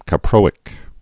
(kə-prōĭk, kă-)